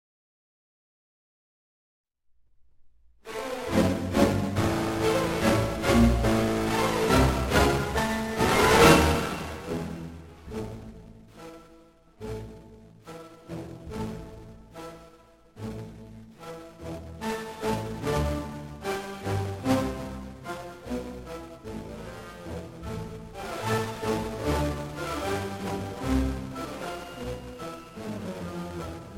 "enPreferredTerm" => "Musique orchestrale"